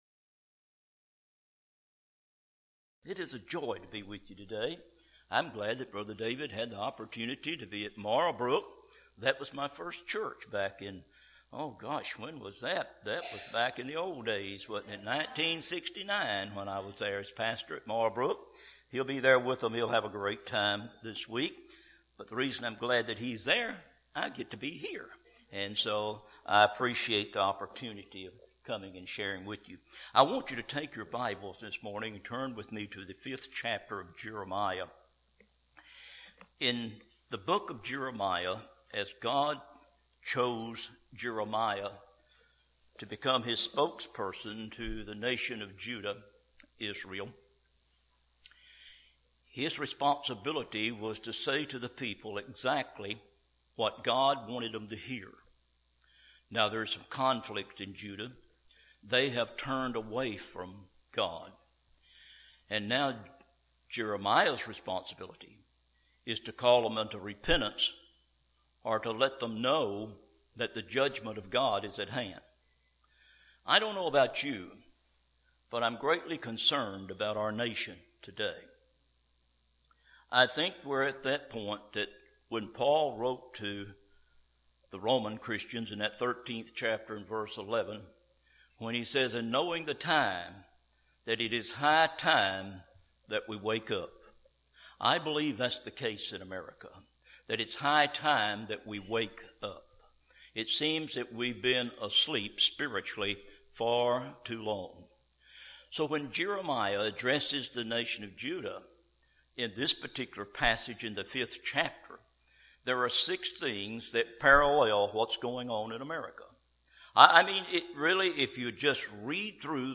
April 17, 2016 – First Baptist Church – Dierks, AR